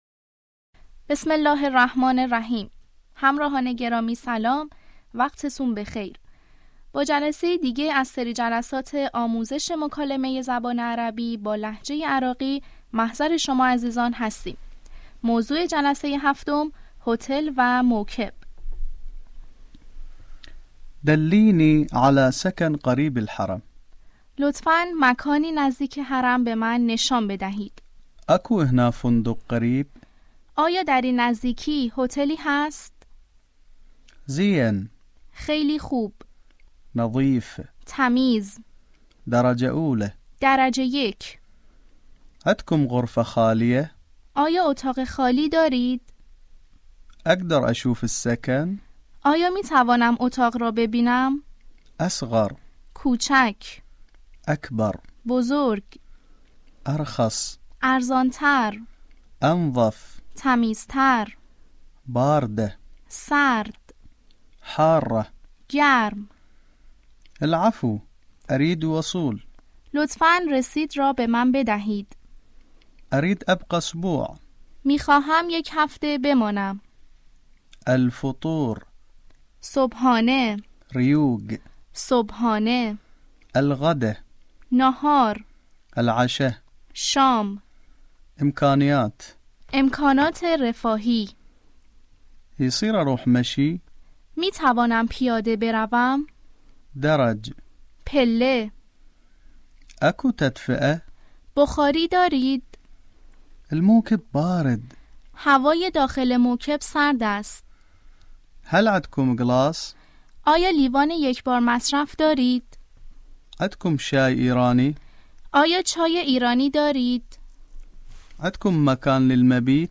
آموزش مکالمه عربی به لهجه عراقی - جلسه هفتم - هتل و موکب